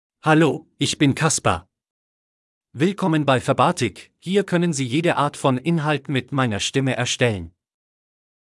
KasperMale German AI voice
Voice sample
Male
German (Germany)
Kasper delivers clear pronunciation with authentic Germany German intonation, making your content sound professionally produced.